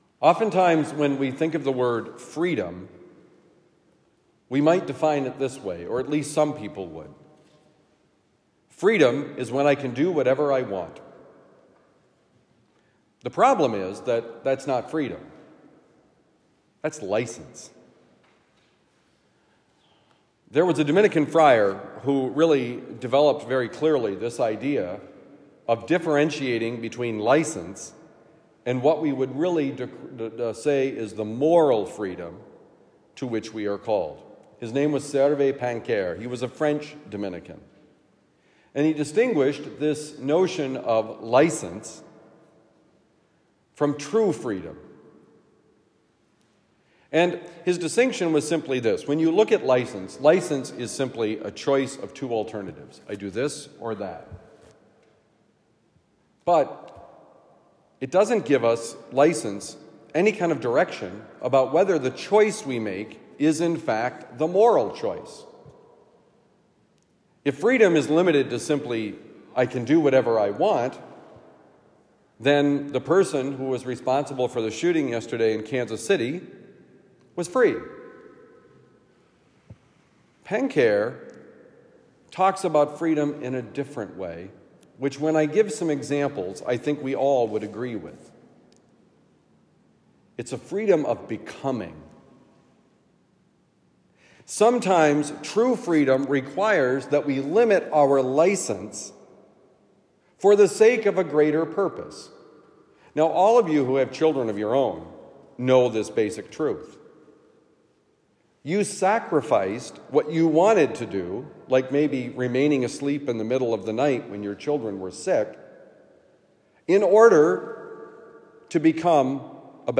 What is Freedom? Homily for Thursday, February 15, 2024